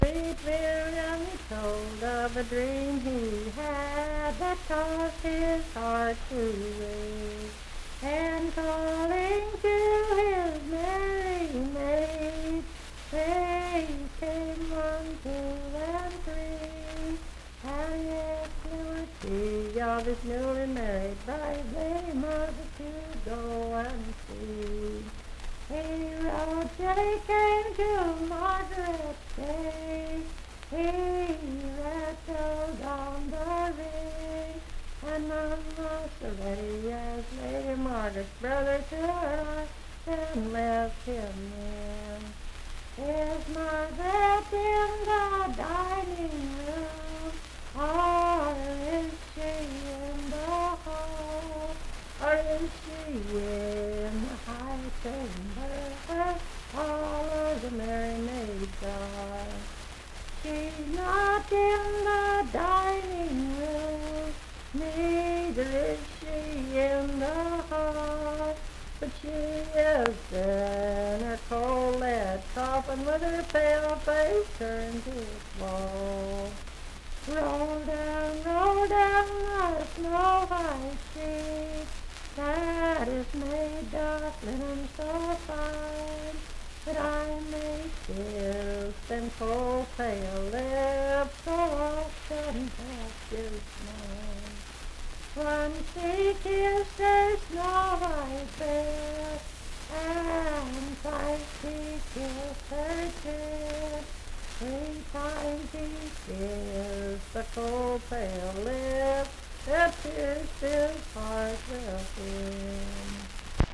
Unaccompanied vocal music
Verse-refrain 7(4).
Voice (sung)